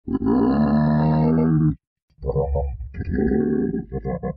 Sound Effects
Giraffe Sound